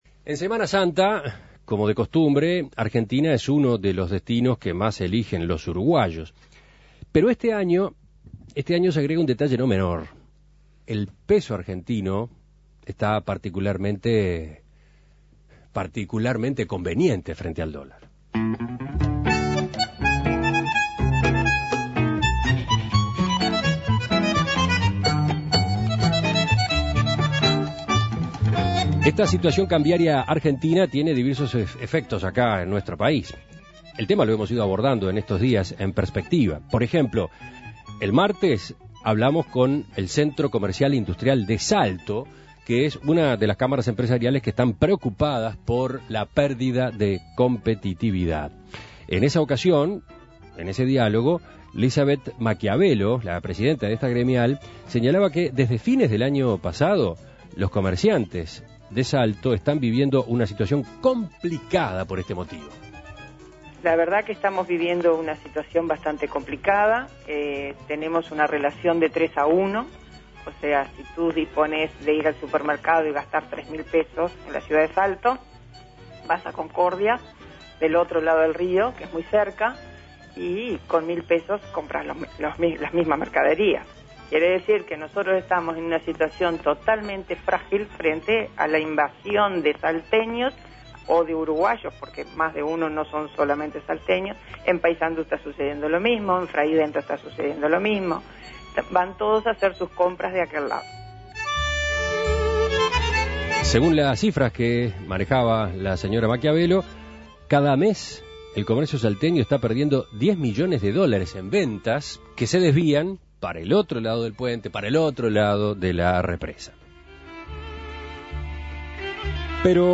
Informes Turismo de uruguayos a Argentina: ¿Qué impacto tiene la mayor ventaja cambiaria?